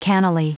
Prononciation du mot cannily en anglais (fichier audio)
Prononciation du mot : cannily